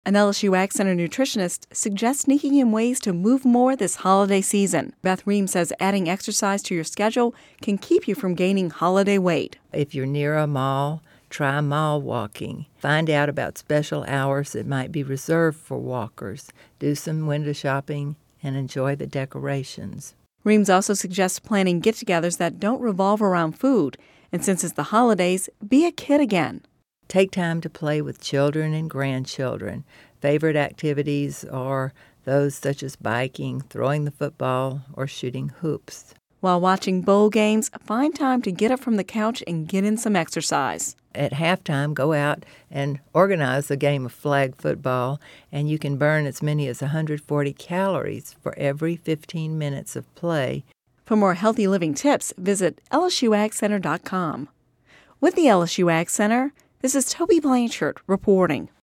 (Radio News 12/15/10) An LSU AgCenter nutritionist suggests sneaking in ways to move more this holiday season.